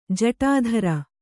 ♪ jaṭādhara